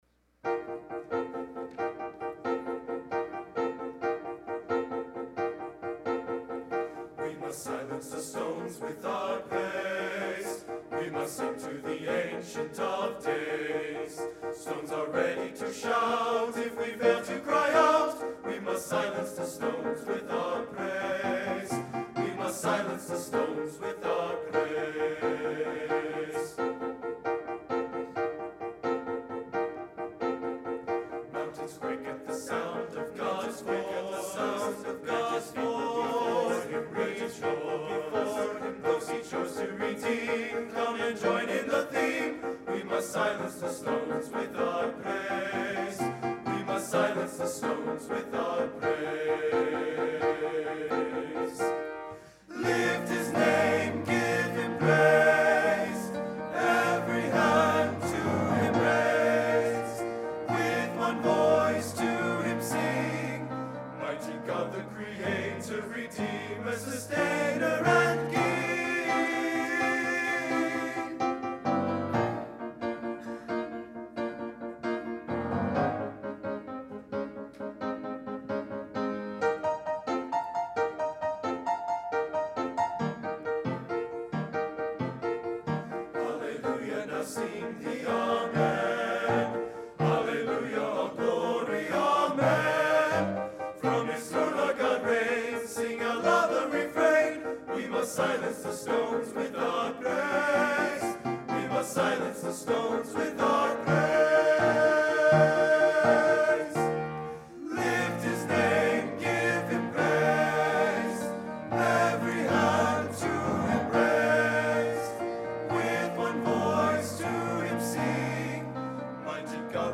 Cedarville University Men's Glee Choir